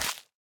Minecraft Version Minecraft Version 25w18a Latest Release | Latest Snapshot 25w18a / assets / minecraft / sounds / block / big_dripleaf / break4.ogg Compare With Compare With Latest Release | Latest Snapshot
break4.ogg